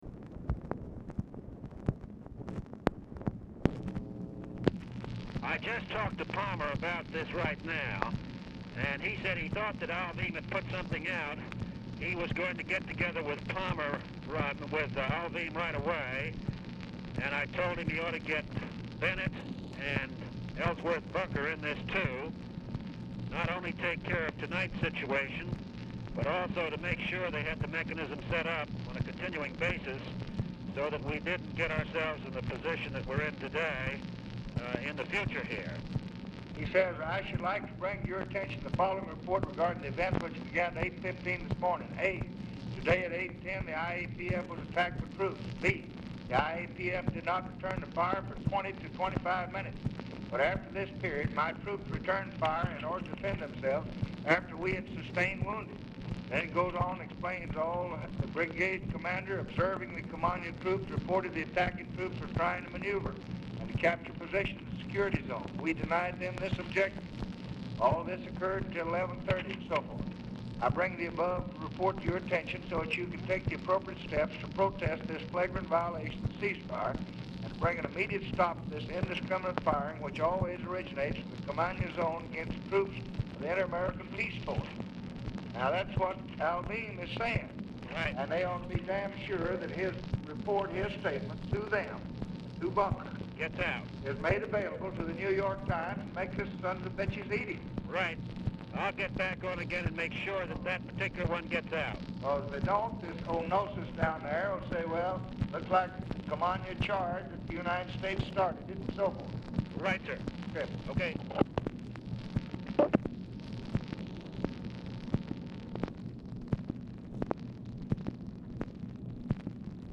Oval Office or unknown location
"SUMMARIZED"; RECORDING STARTS AFTER CONVERSATION HAS BEGUN
Telephone conversation
Dictation belt